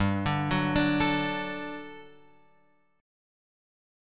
GM7sus2 chord {3 5 4 x 3 5} chord